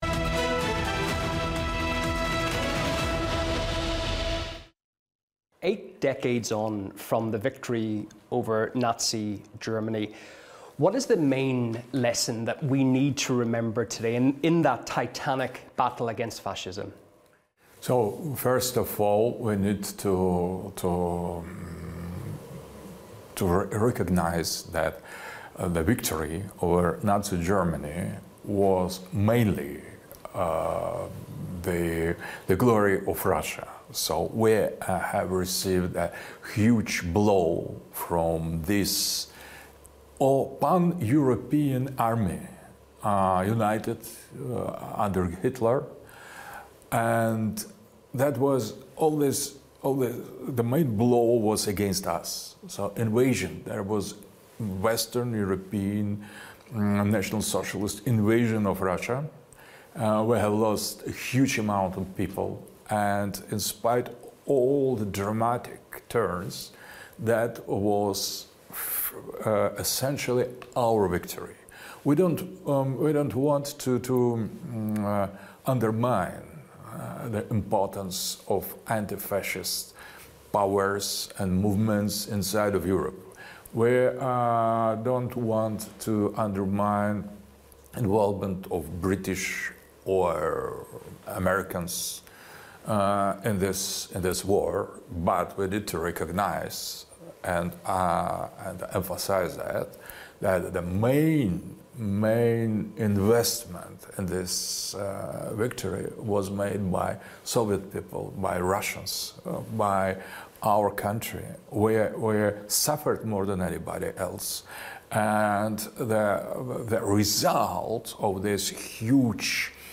RT speaks with Russian philosopher Aleksandr Dugin, raising historic memory about victory over Nazism.